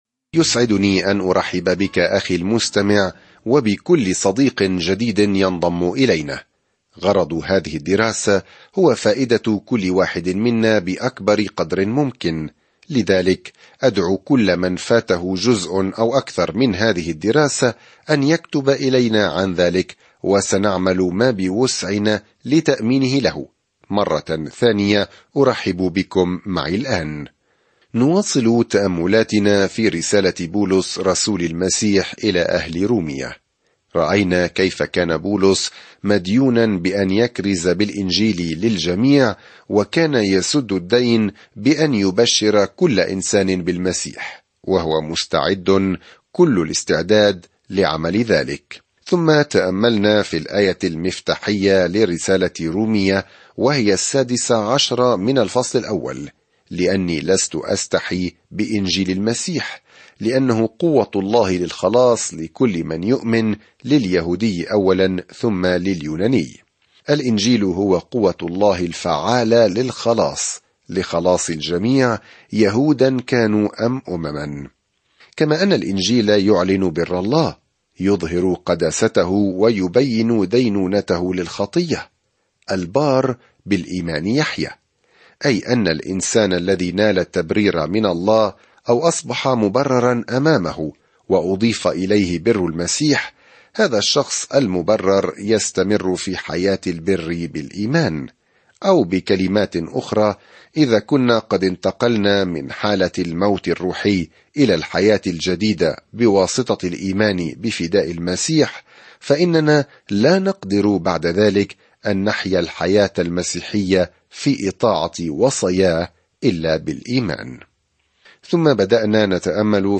الكلمة رُومِيَةَ 21:1-32 يوم 4 ابدأ هذه الخطة يوم 6 عن هذه الخطة الرسالة إلى أهل رومية تجيب على السؤال: "ما هي البشارة؟" وكيف يمكن لأي شخص أن يؤمن، ويخلص، ويتحرر من الموت، وينمو في الإيمان. سافر يوميًا عبر رسالة رومية وأنت تستمع إلى الدراسة الصوتية وتقرأ آيات مختارة من كلمة الله.